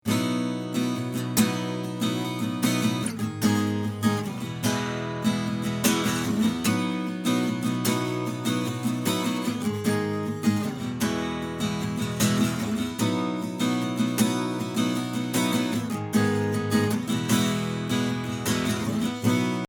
Acoustic Guitar Recording
So I used a pretty crappy acoustic with and mxl 990 about six inches away from the 12th fret. I added a little bit of compression, some eq, and some reverb.